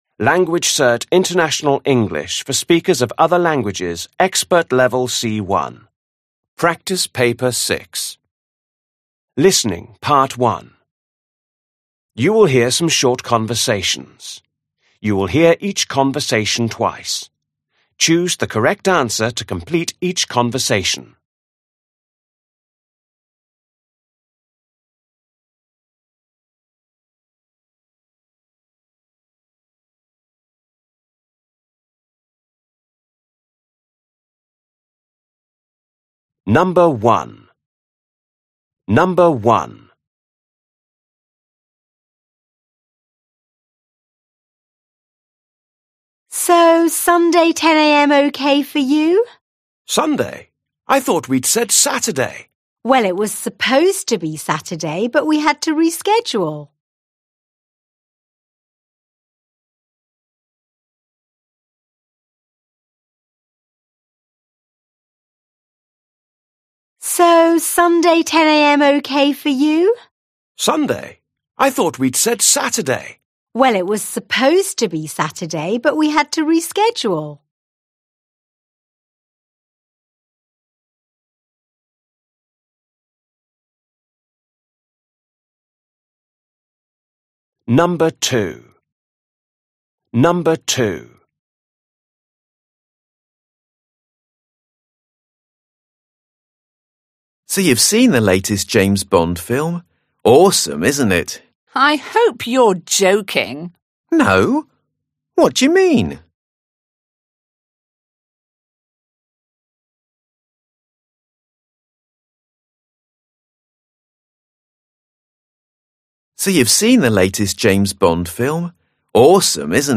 You will hear some short conversations. You will hear each conversation twice. Choose the